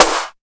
78 SNARE 2.wav